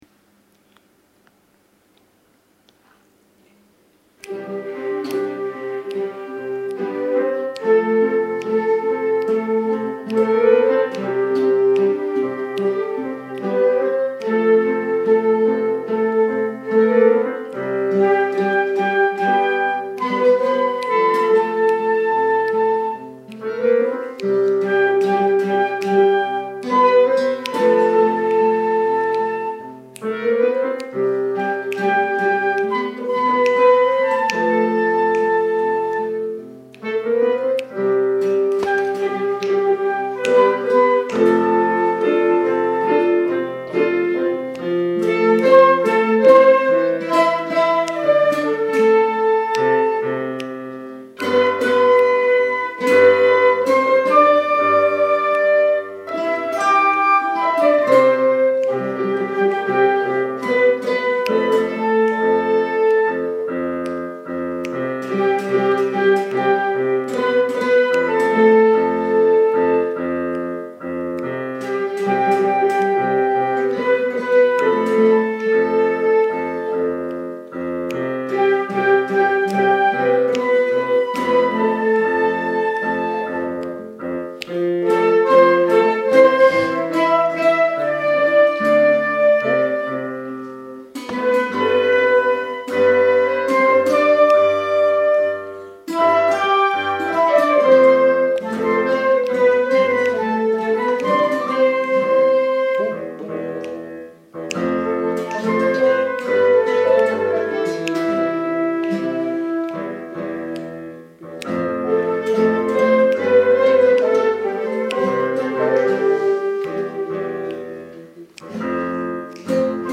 Flauta travesera
Clarinete en Si bemol 1
Guitarra
Piano
- Compás: 4/4.
- Tonalidad: Do Mayor
Interpretaciones en directo.
Entera_tutti_en_clase.MP3